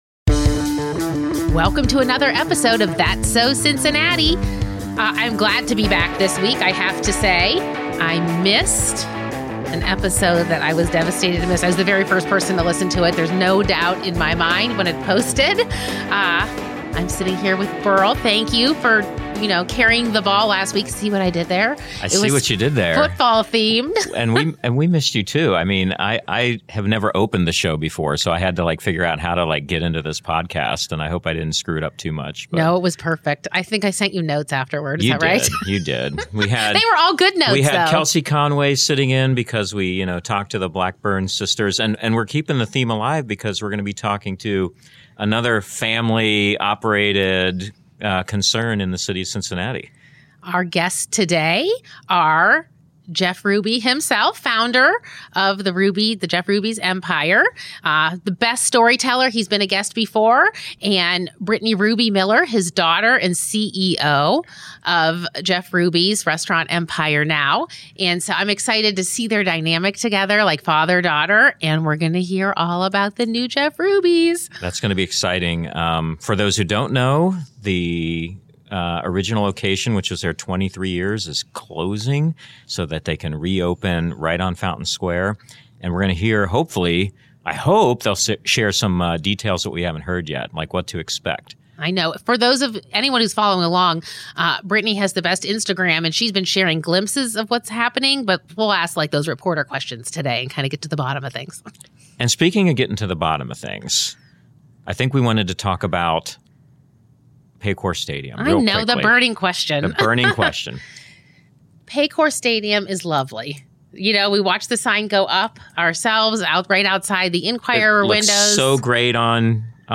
Ahead of the Legislature returning to session on Tuesday for the first time in six weeks, Ohio House Majority Leader Bill Seitz of Green Township joined The Enquirer's That's So Cincinnati podcast to talk about the role GOP lawmakers, DeWine and Acton are playing in reopening the economy.
The Seitz interview begins at the 30:15 mark in the episode.